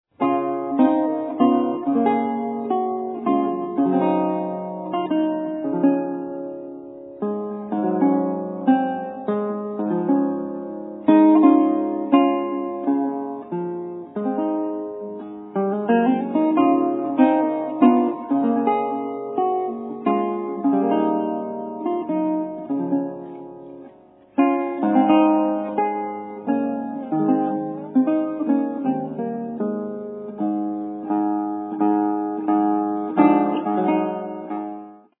19th CENTURY GUITAR